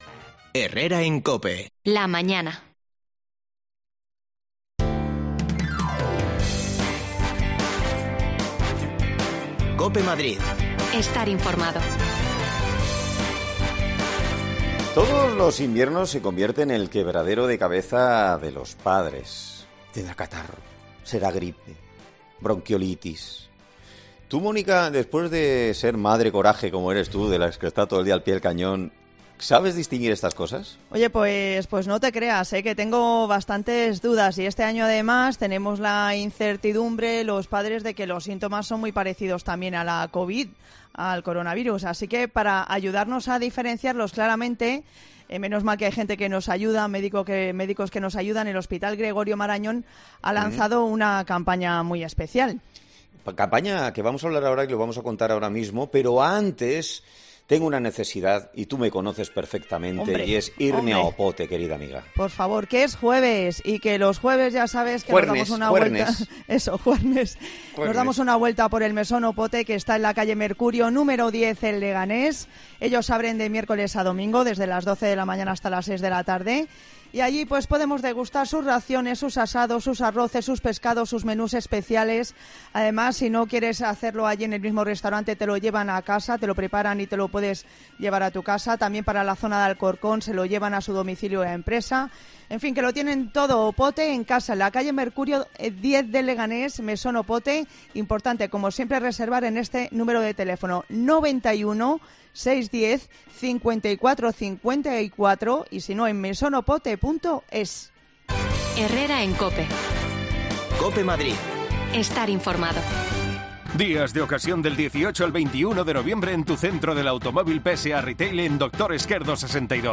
Herrera en COPE Madrid
Las desconexiones locales de Madrid son espacios de 10 minutos de duración que se emiten en COPE , de lunes a viernes.